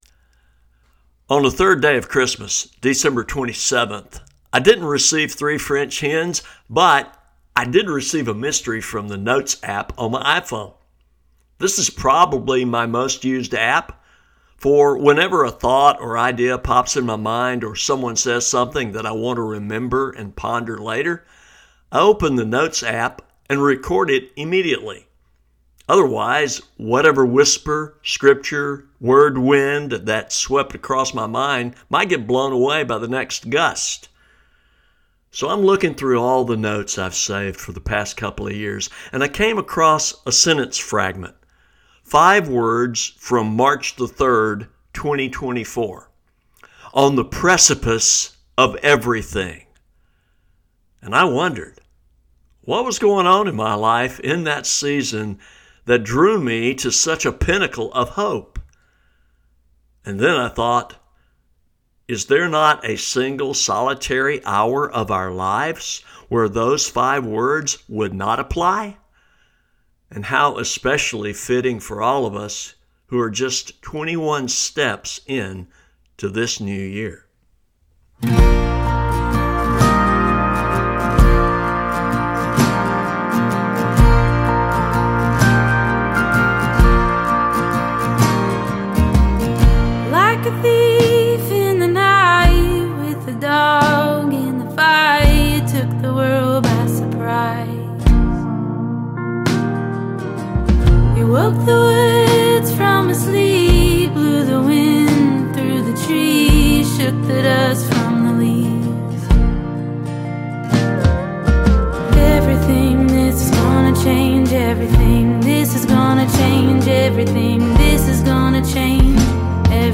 Today’s episode features music by one of my favorite singer-songwriters